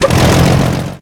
tank-engine-load-reverse-1.ogg